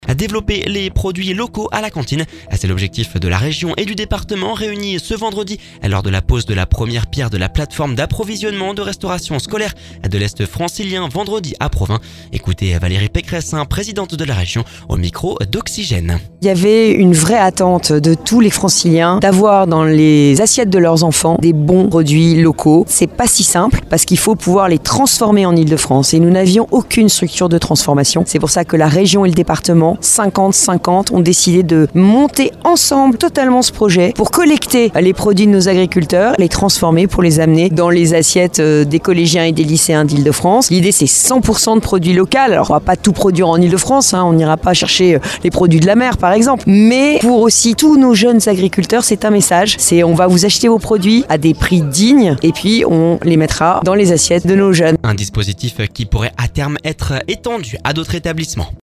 Écouter le podcast Télécharger le podcast C’est l’objectif de la région et du département réuni ce vendredi lors de la pose de la première pierre de la plateforme d'approvisionnement de restauration scolaire de l'est francilien vendredi à Provins. Ecoutez Valérie Pécresse, présidente de la région au micro d’Oxygène.